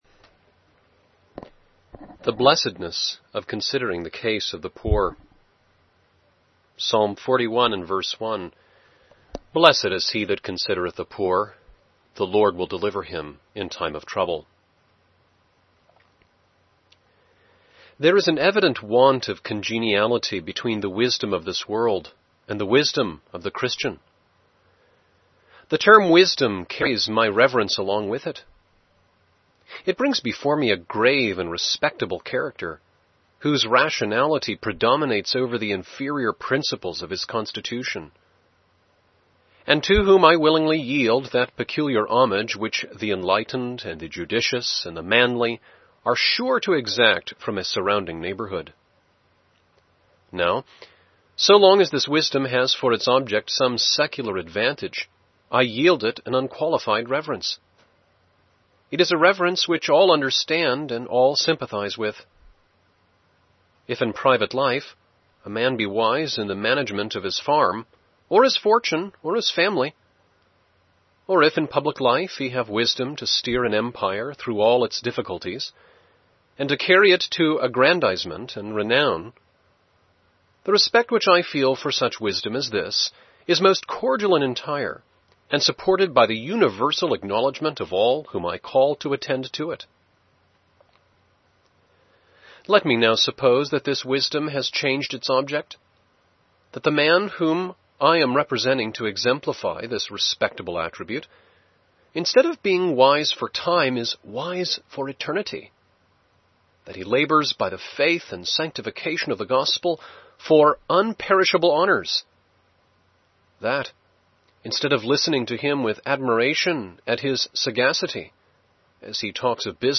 Here is a sermon Thomas Chalmers preached to a benevolent society that sets forth his principles for Christian benevolence. He advocates at once a very practical, thorough-going humanitarianism, steering a course between the pitfalls of merely throwing cash at poverty on the one hand and a this-worldly focus on outward needs (anticipating the Social Gospel?).